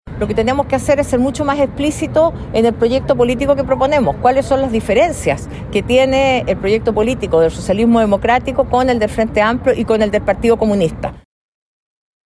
En ese sentido, la senadora y presidenta del Partido Socialista, Paulina Vodanovic, quien también preside el comité político del comando de Tohá, planteó la ofensiva.